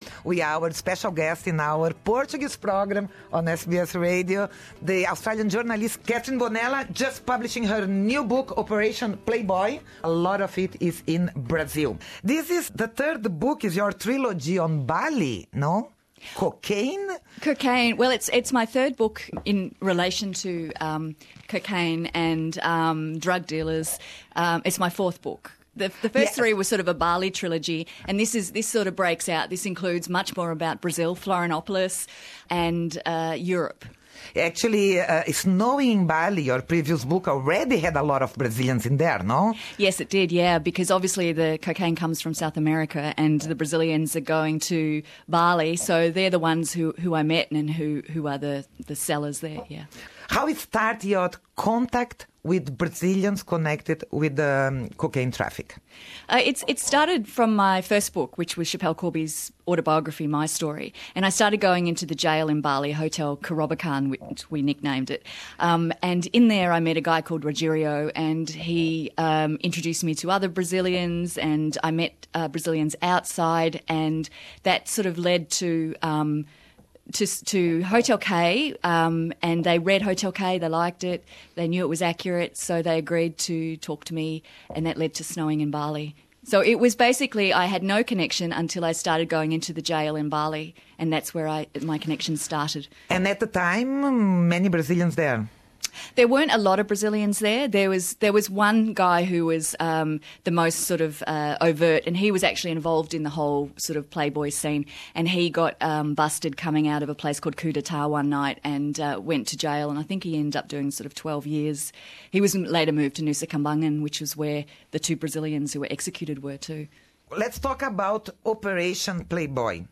Facebook live interview